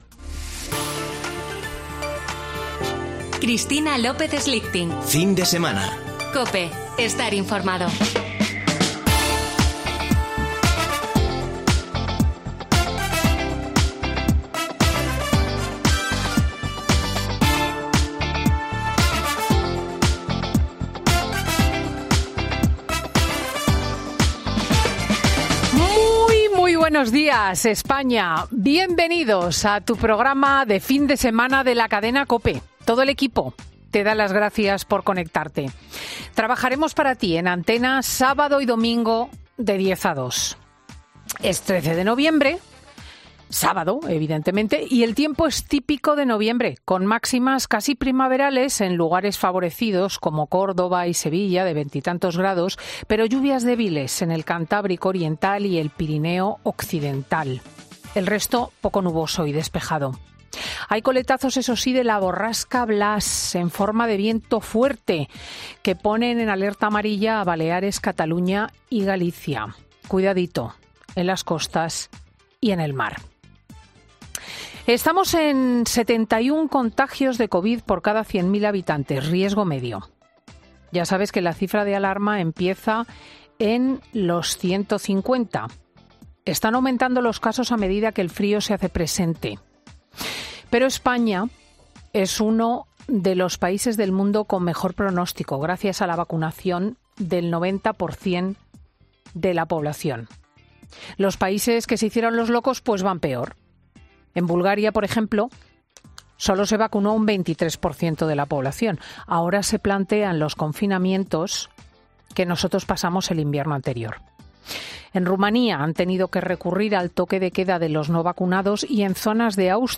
AUDIO: Ya puedes escuchar el monólogo de Cristina López Schlichting en 'Fin de Semana' COPE